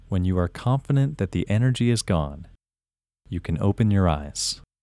IN – First Way – English Male 31
IN-1-English-Male-31.mp3